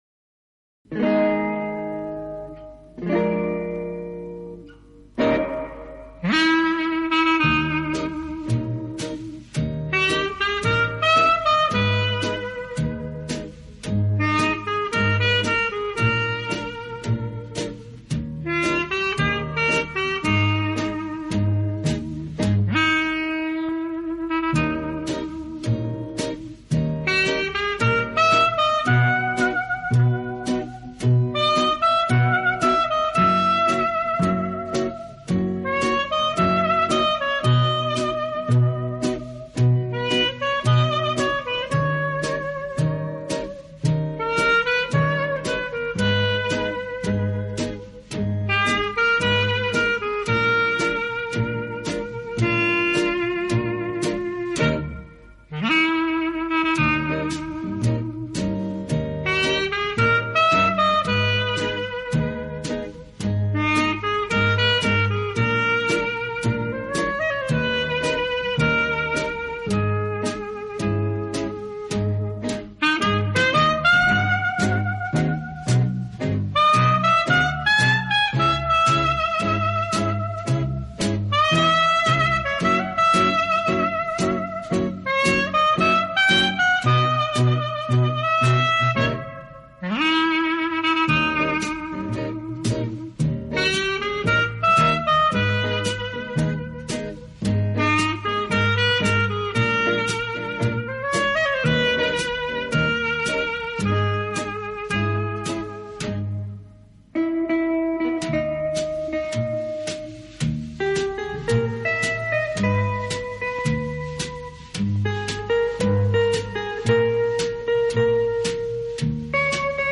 1976 | Instrumental |